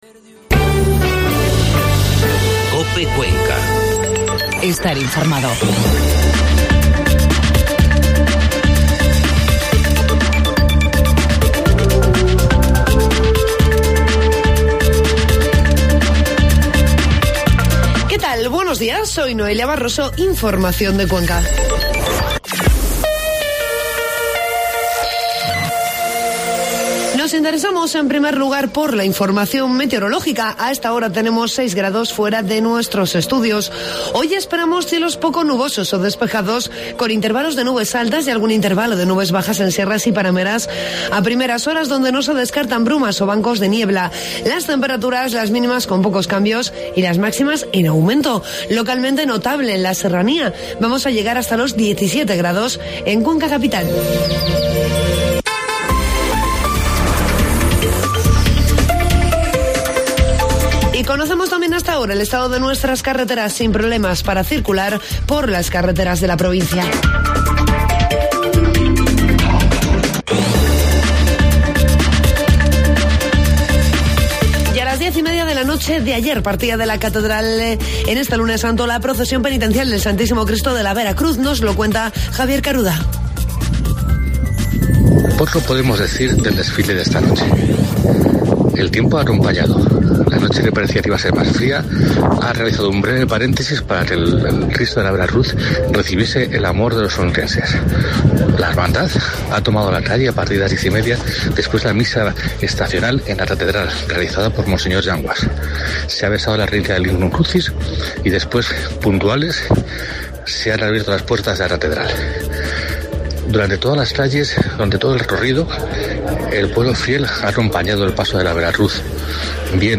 Informativo matinal COPE Cuenca 27 de marzo